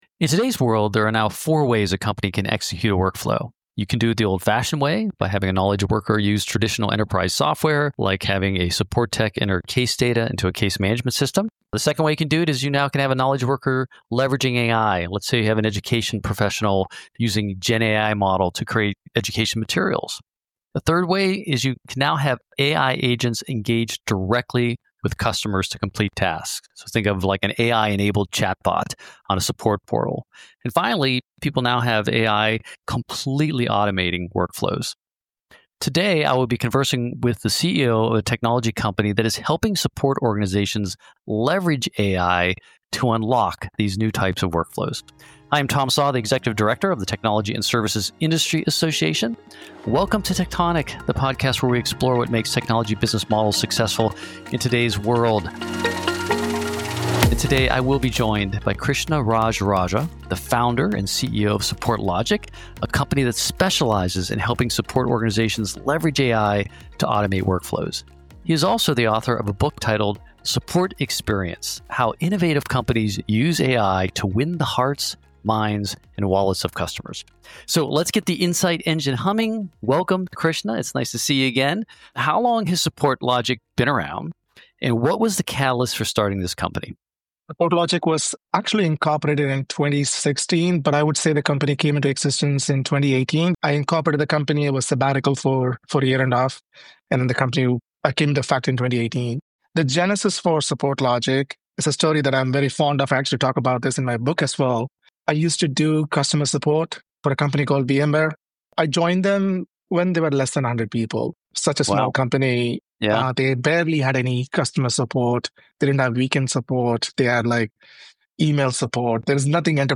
From key takeaways and the most impactful breakout session presentations to the pulse from countless conversations with attendees throughout the week, this framing conversation will add depth and insights to the content that was presented.